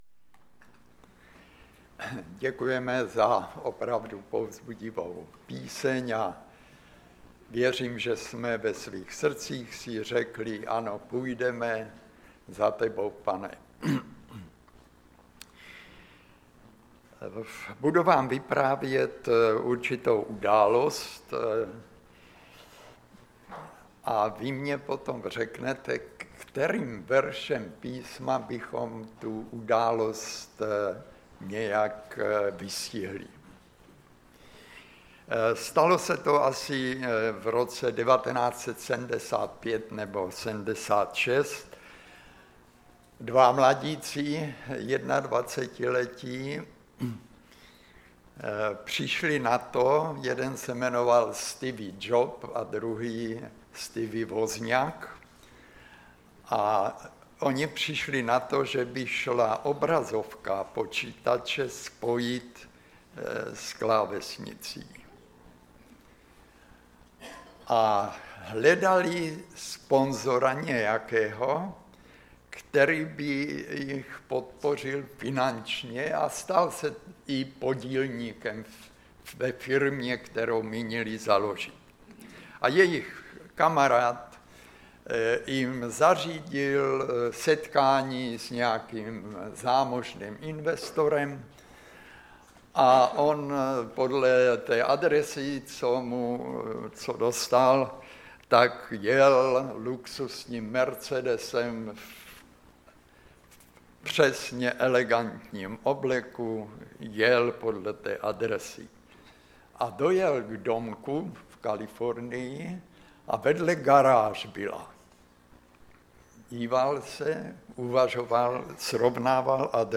Kazatel